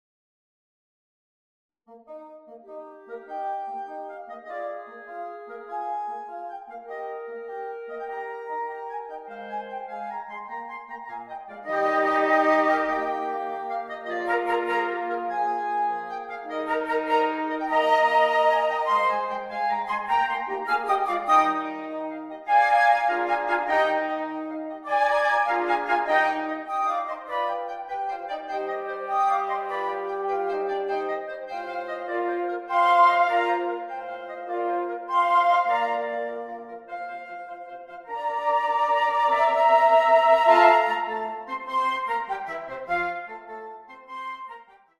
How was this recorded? (Audio generated by Sibelius)